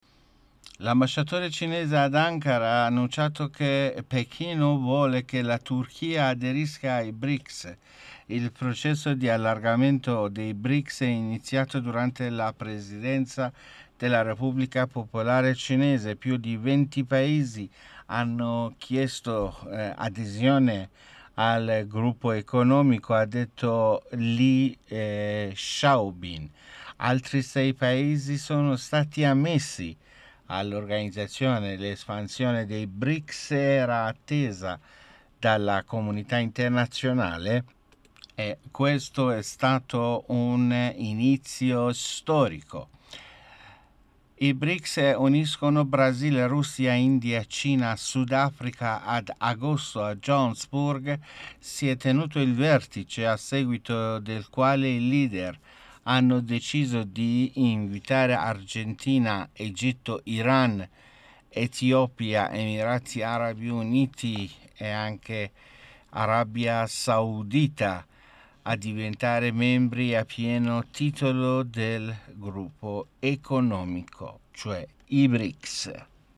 Notiziario / mondo